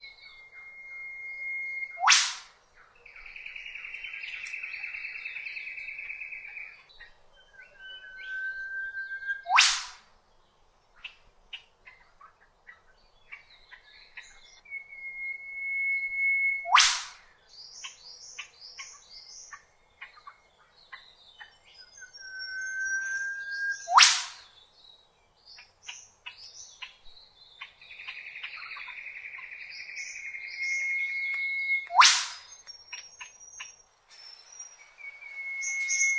File:Eastern Whipbird.ogg
English: Audio recording of the call of a bird, the male Eastern Whipbird, a native bird that inhabits much of the forested areas of the east coast of Australia. As the name implies - and as you will hear - its call resembles the loud cracking of a whip.
Between the loud whip-cracks, you hear chatter of other birds in the area and, right at the end, the triple call of the female Eastern Whipbird, responding to the male's call. It was originally recorded on a Sony MiniDisc Walkman circa 2000 and transferred to my computer. This recording was made on the slopes of the Illawarra Range, behind the northern suburbs of Wollongong, N.S.W., Australia, close to Broker's Nose.